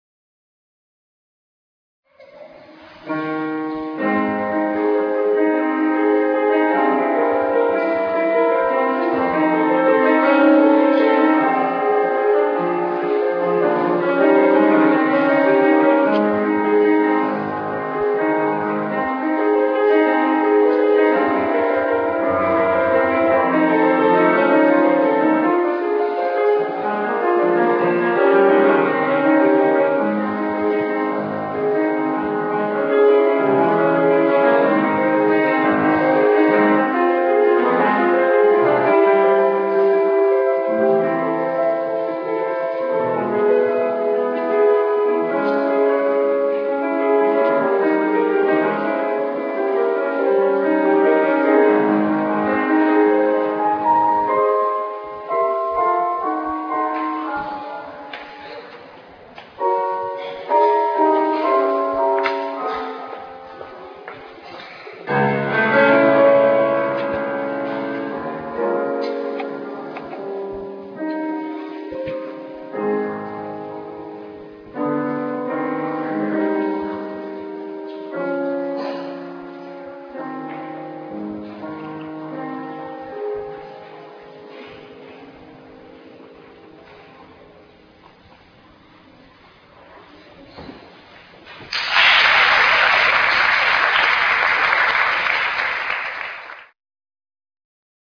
小さな音楽会
会場：あいれふホール